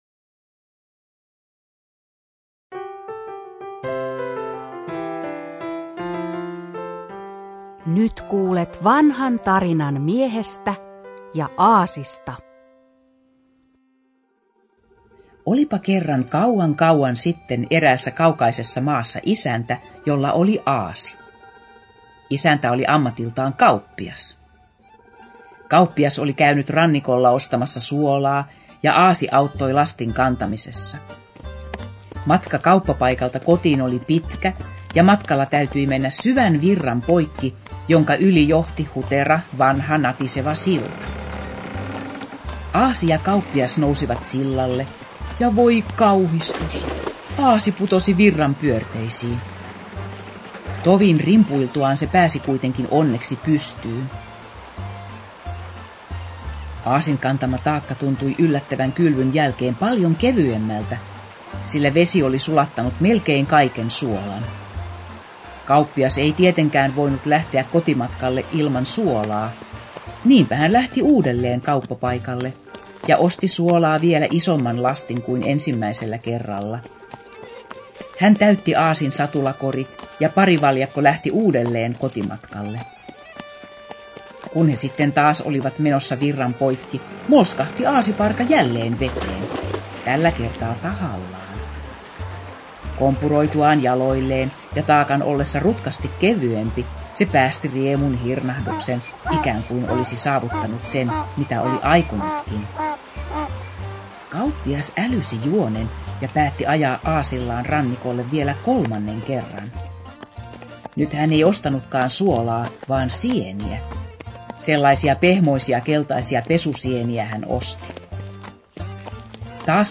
Kuunnelma: Mies ja aasi